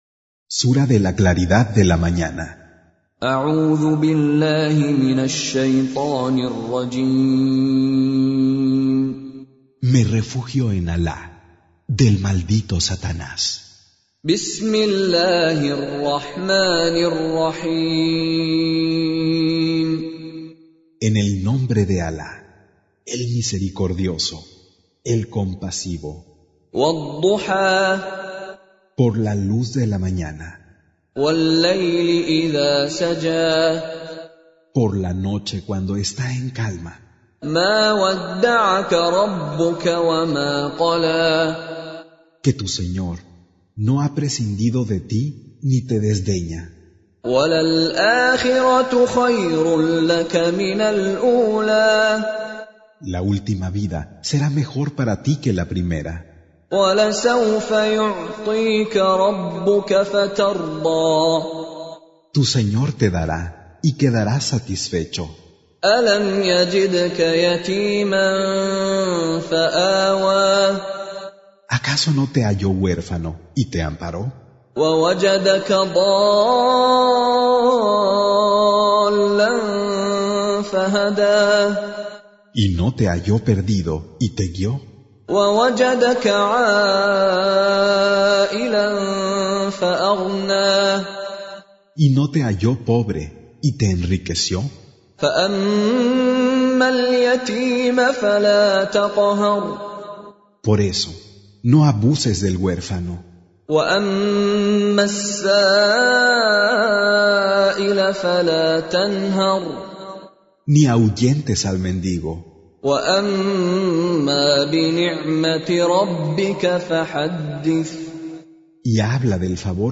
Surah Repeating تكرار السورة Download Surah حمّل السورة Reciting Mutarjamah Translation Audio for 93. Surah Ad-Duha سورة الضحى N.B *Surah Includes Al-Basmalah Reciters Sequents تتابع التلاوات Reciters Repeats تكرار التلاوات